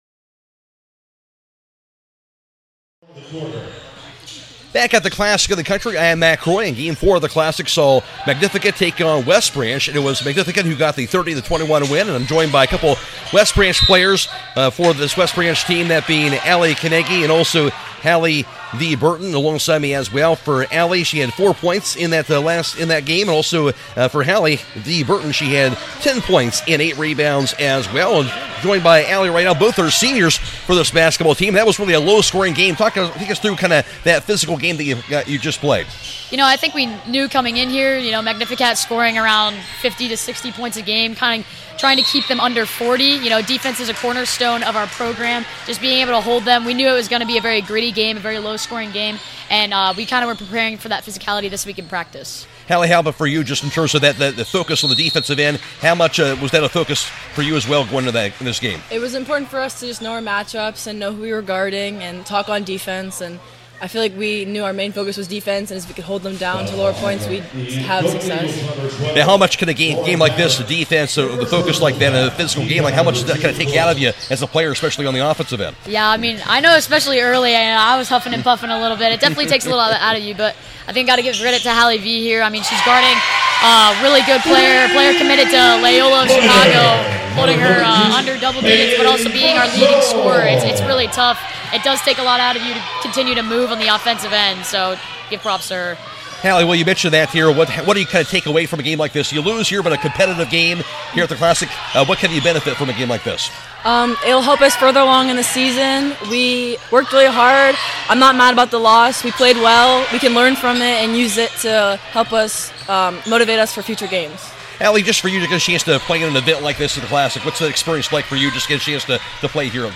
CLASSIC 2026 – WEST BRANCH PLAYER INTERVIEWS